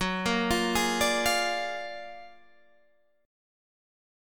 Gb+M9 Chord